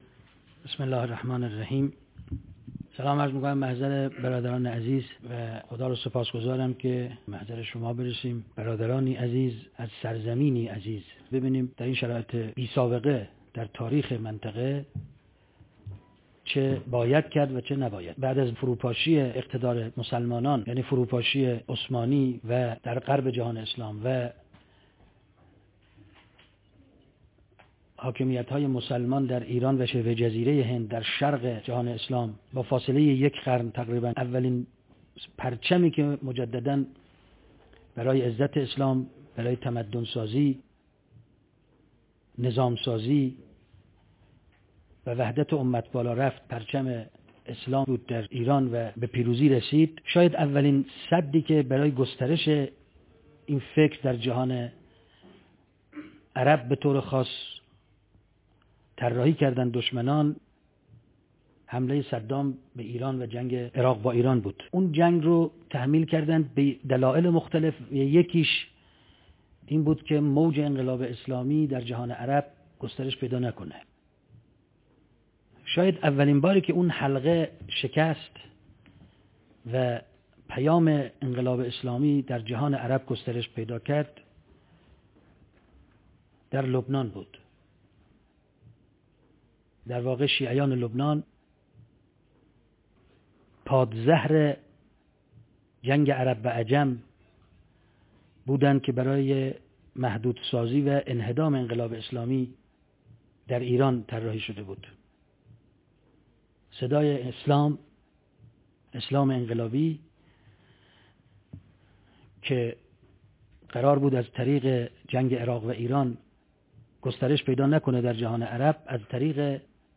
نشست (پروژه‌های صهیونیستی در جهان اسلام) _ دانشجویانی از کشورهای عربی _ ۱۳۹۳